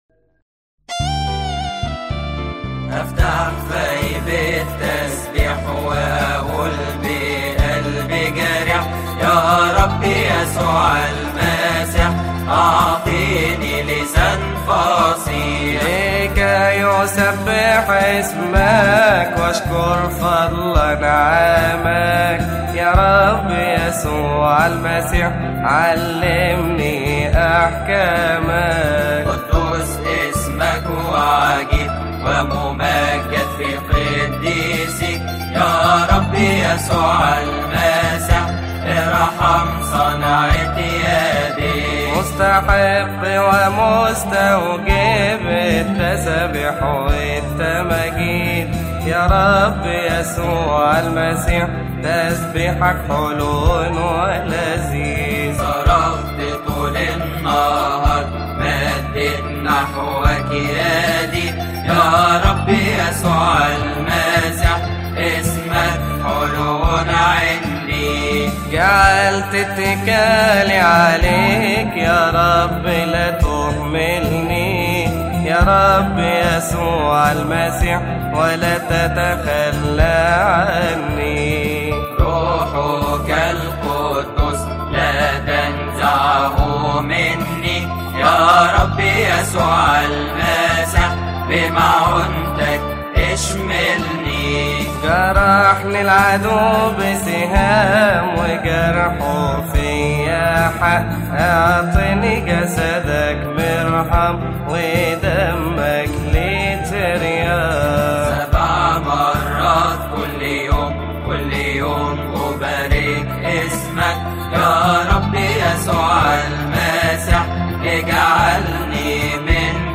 • المصدر : فريق ابو فام الجندي
مديح أفتح فاي بالتسبيح يقال في تسبحة نصف الليل بشهر كيهك لفريق ابو فام الجندي، عربي.
المصدر: فريق ابو فام الجندي